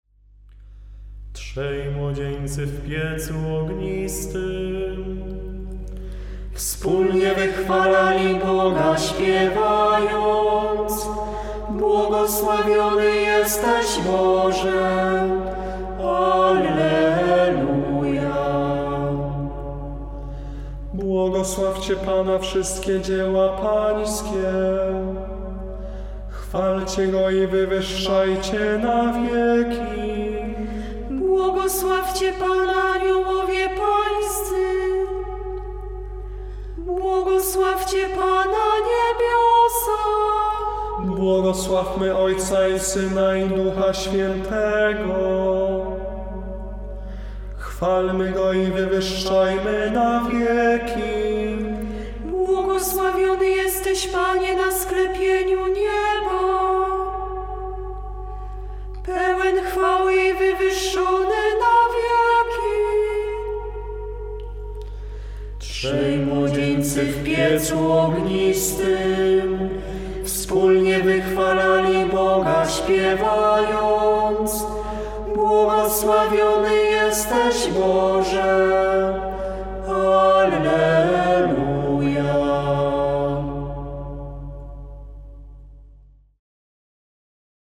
PSALMODIA